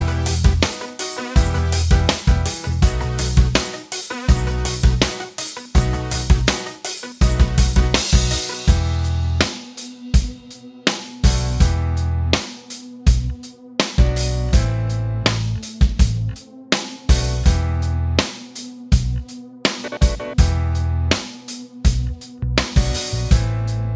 Minus All Guitars Pop (2000s) 3:23 Buy £1.50